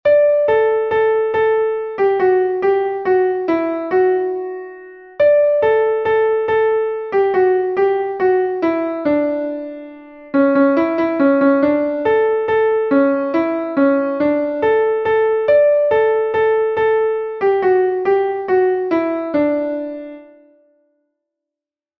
Melodie: alte Tanzweise aus dem Elsass